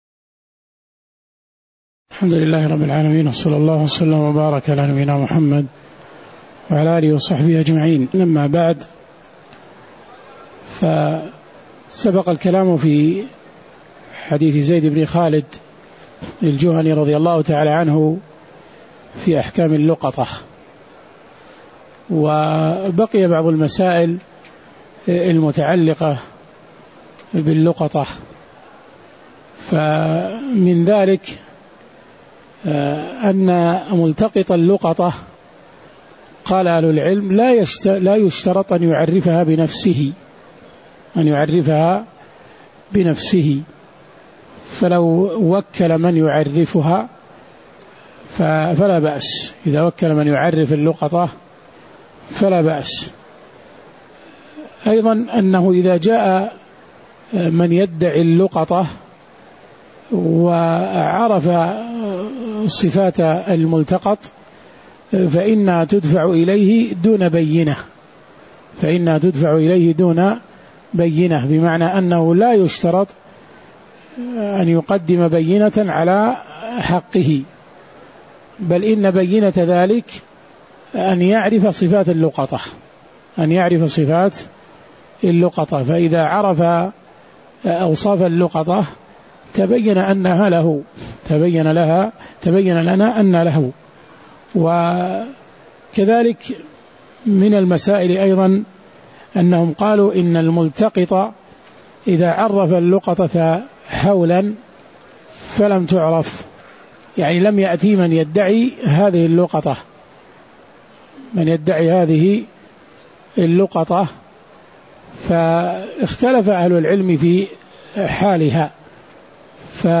تاريخ النشر ٨ رجب ١٤٣٩ هـ المكان: المسجد النبوي الشيخ